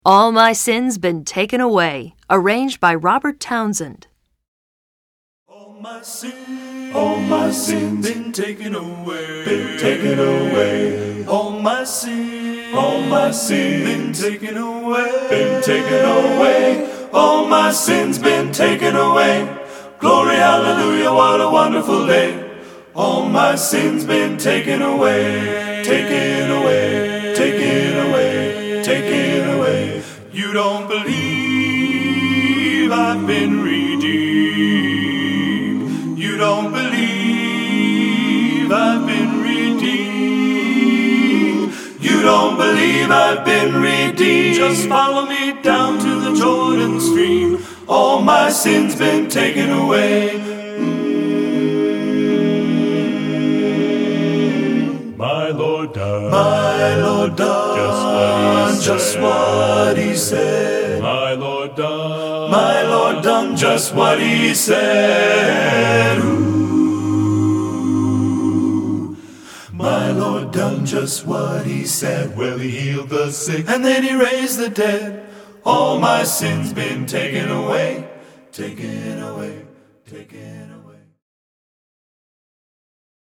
Composer: Spirituals
Voicing: TTBB